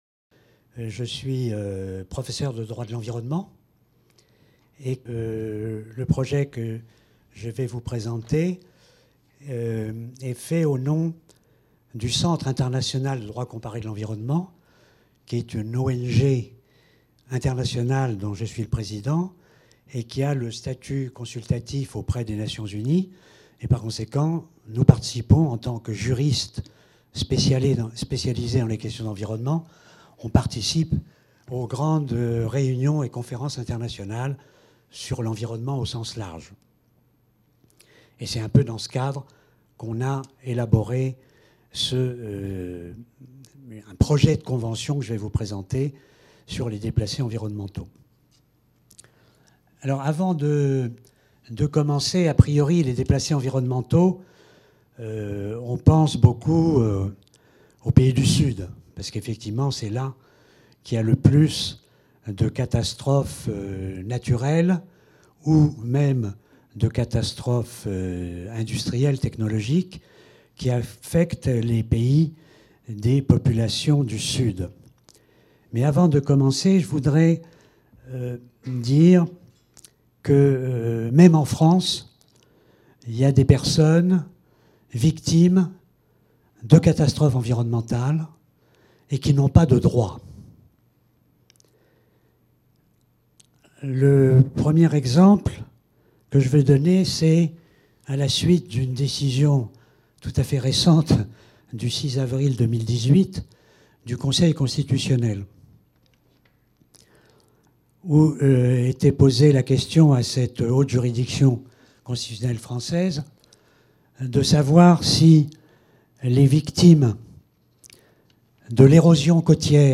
Cette communication a été prononcée dans le cadre du festival "Migrations et climat", qui s'est tenu à Caen du 18 au 20 avril 2018.